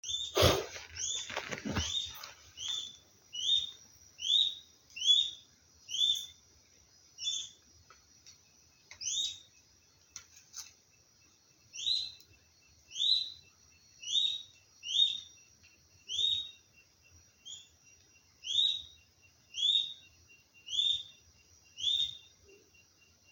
Olivaceous Woodcreeper (Sittasomus griseicapillus)
Location or protected area: Reserva Privada y Ecolodge Surucuá
Condition: Wild
Certainty: Recorded vocal